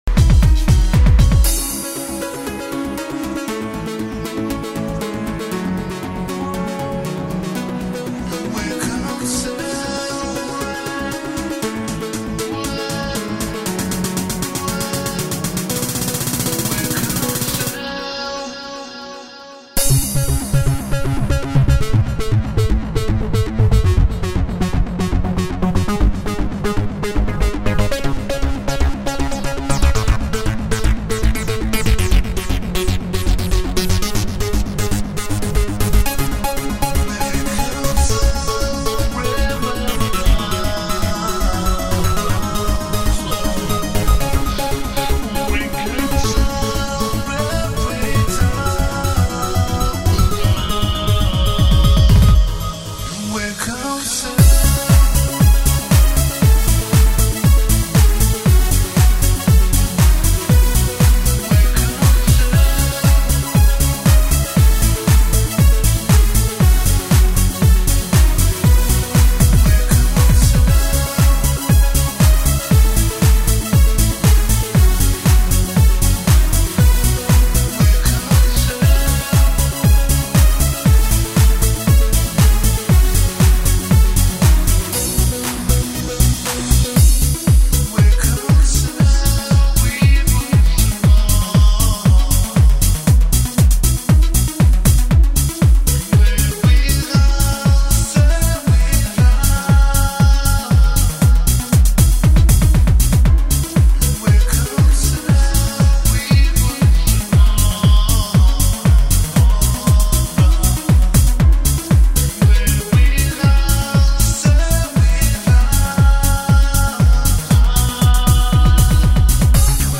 Exclusively and for you from the depths of my vocal chords:(It's the first time and the last I dare to sing)